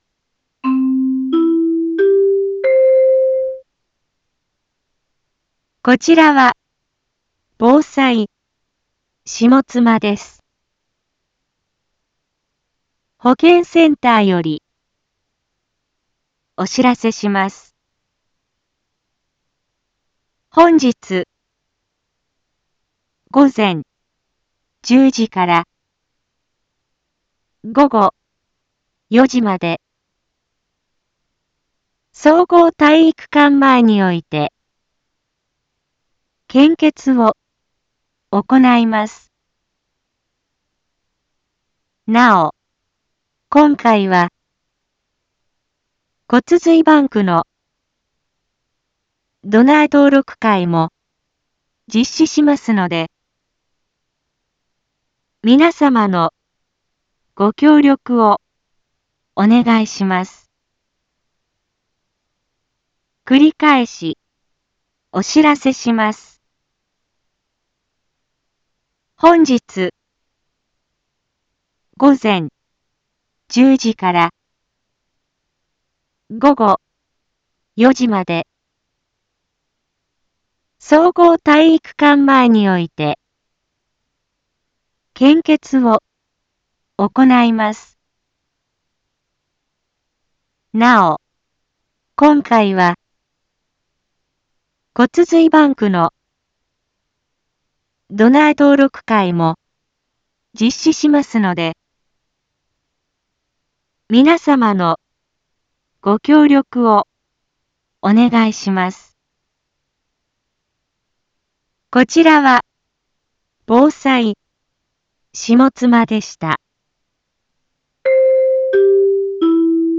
一般放送情報
BO-SAI navi Back Home 一般放送情報 音声放送 再生 一般放送情報 登録日時：2022-06-23 09:02:02 タイトル：献血のお知らせ（当日報） インフォメーション：こちらは防災下妻です。